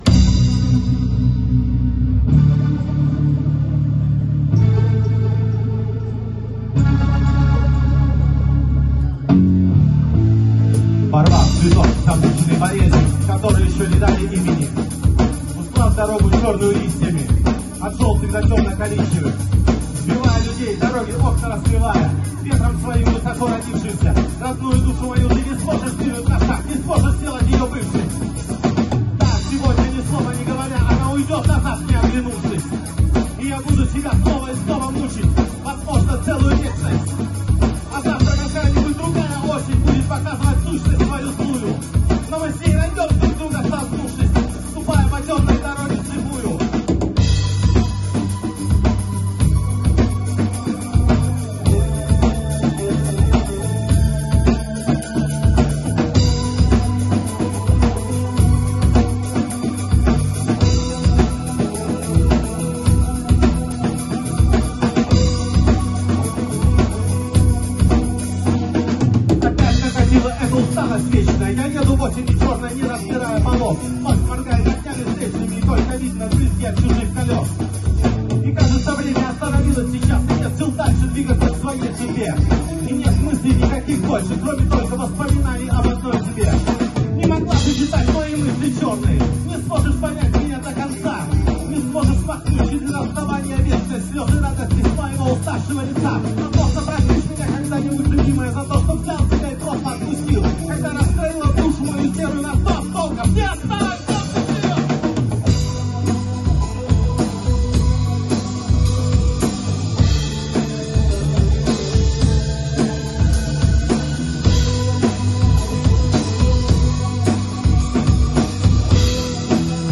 Демо записи
живой звук